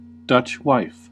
Ääntäminen
IPA : /ˌdʌtʃ ˈwaɪf/